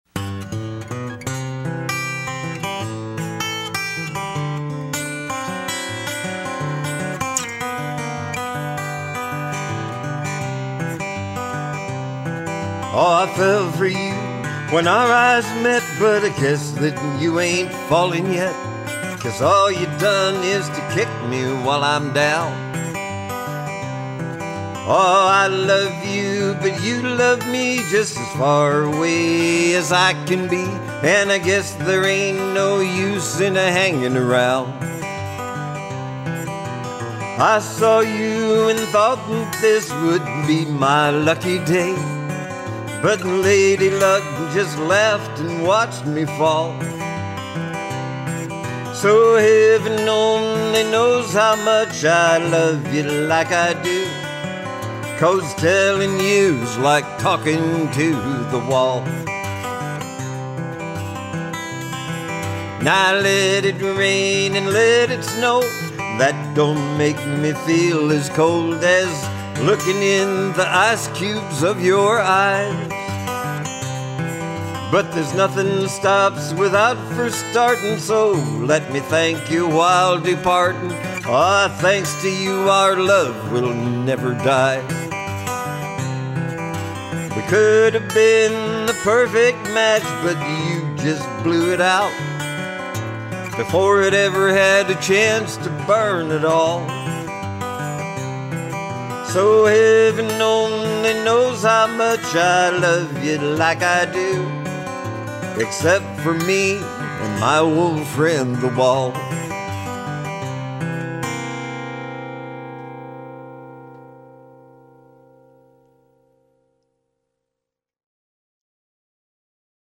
mostly just me and my guitars.